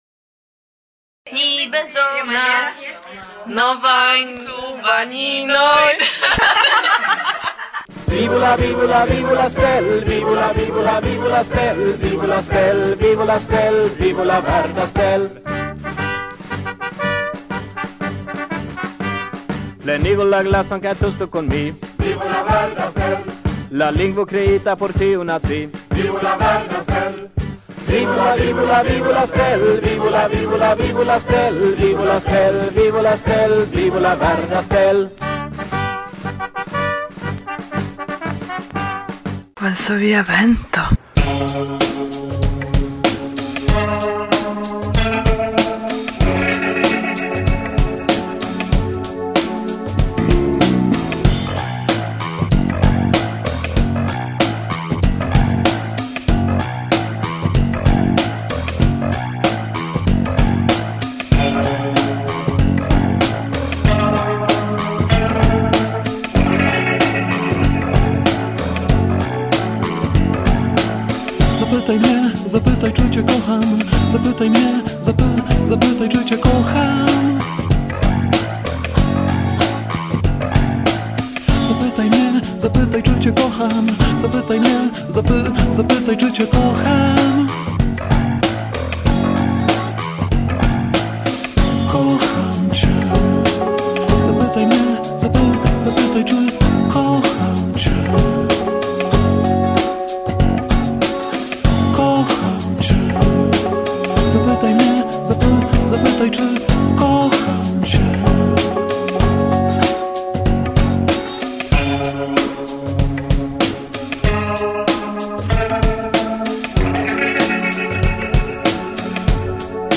El la pola muziko
Momento kun francia muziko
Intervjuo